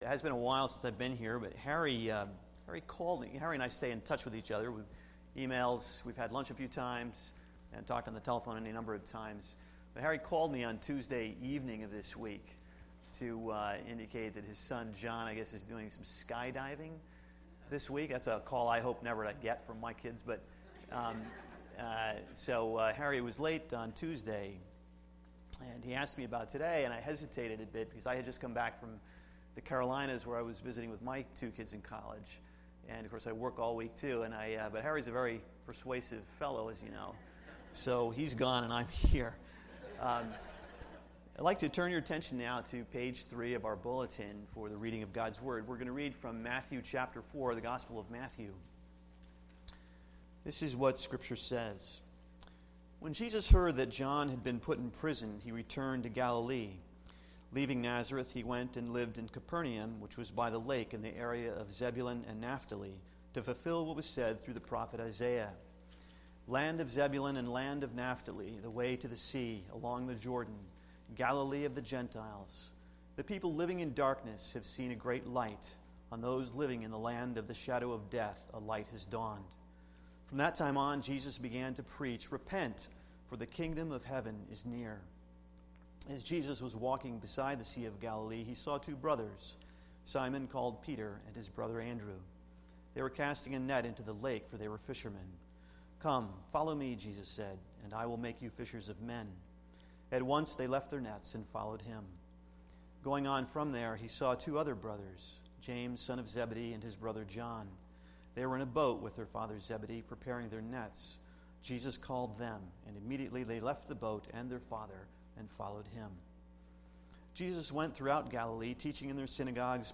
A message from the series "General Topics."